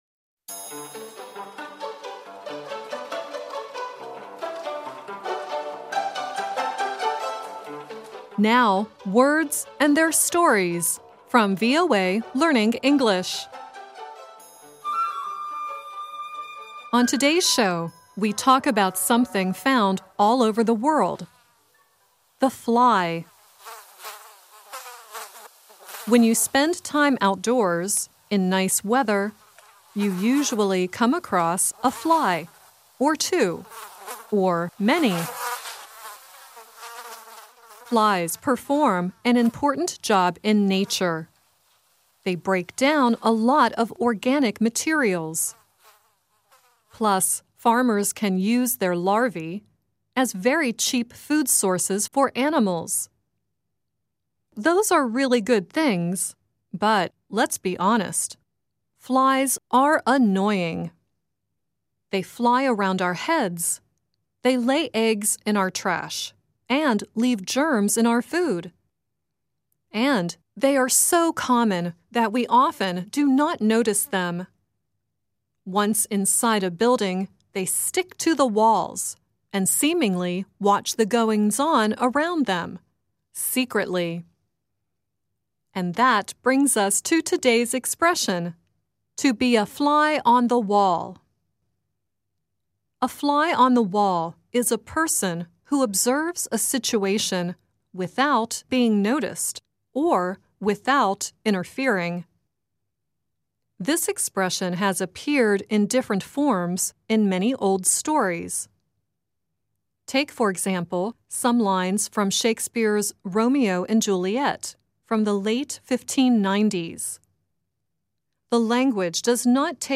Miley Cyrus sings "Fly on the Wall" at the end of the show.